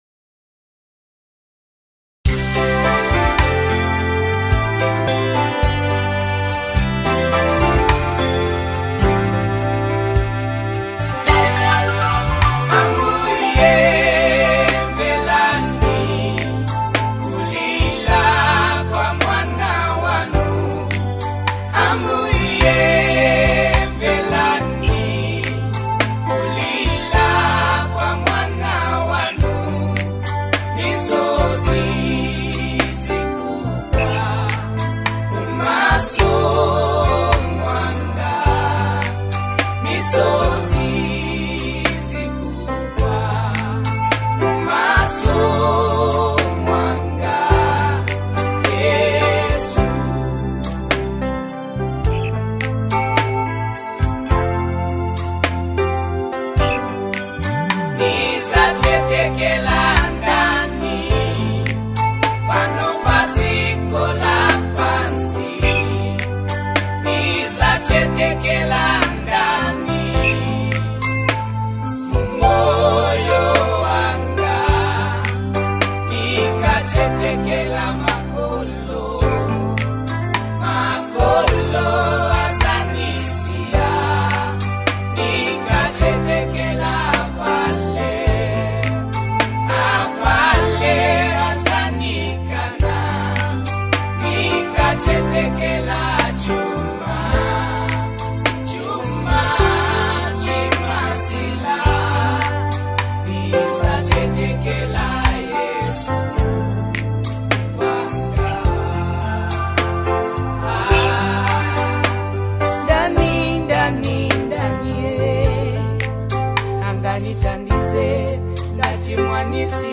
A deeply touching worship song with a timeless message
📅 Category: Zambian Classic Deep Worship Song